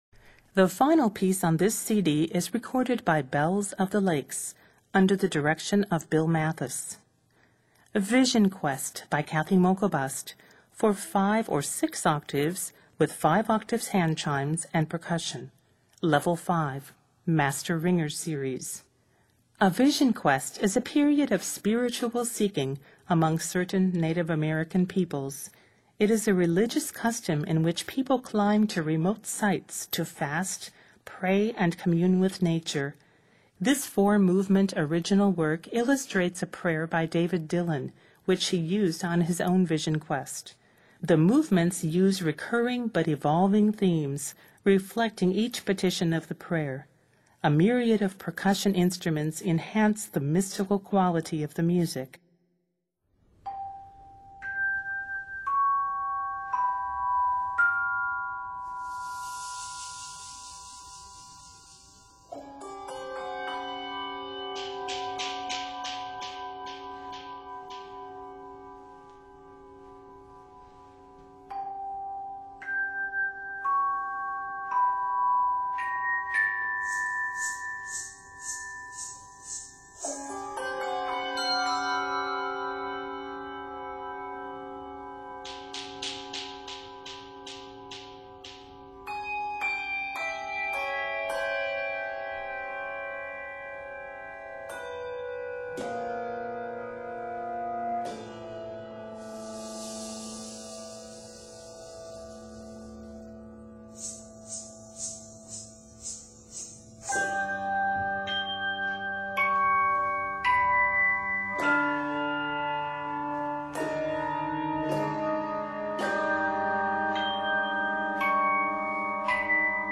four-movement work